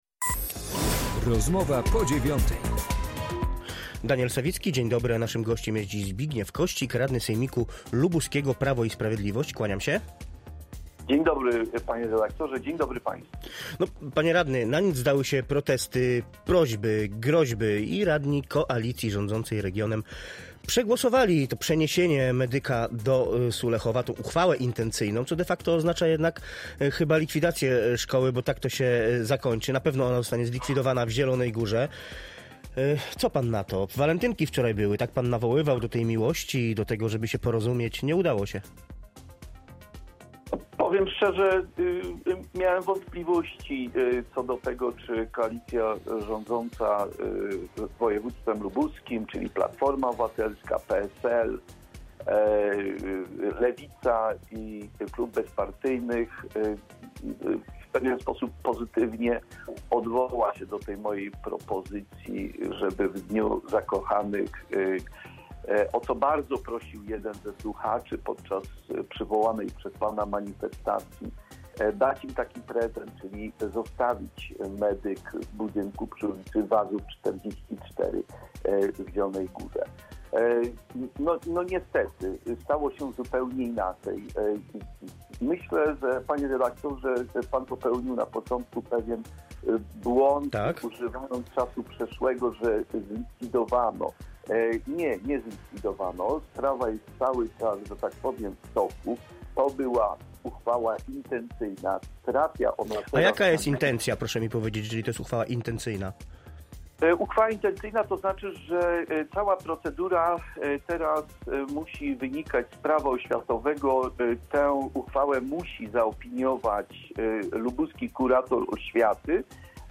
Zbigniew Kościk, radny sejmiku lubuskiego (PiS)